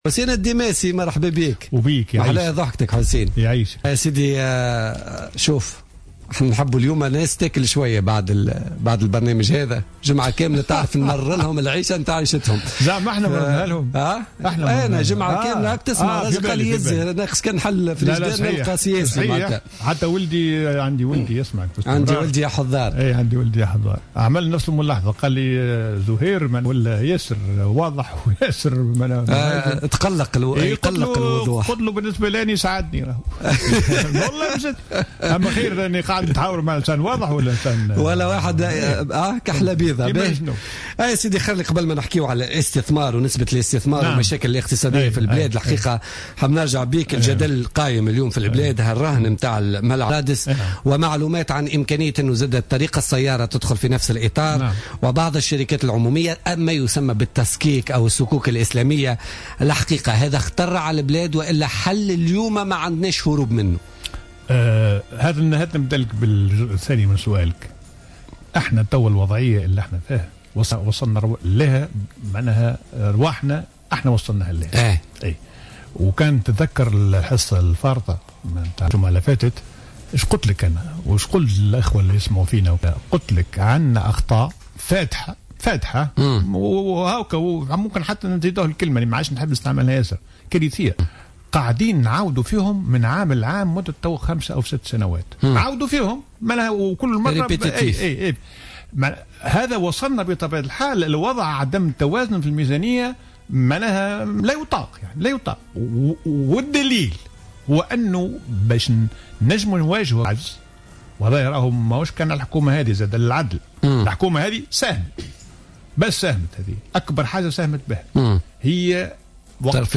أكد الخبير الاقتصادي ووزير المالية السابق حسين الديماسي في تصريح للجوهرة أف أم في برنامج بوليتكا لليوم الجمعة 06 نوفمبر 2015 أن الصكوك الإسلامية هي الحل الخطير الذي بقي للدولة اليوم في ظل الوضعية الاقتصادية "الكارثية " التي تعيشها البلاد.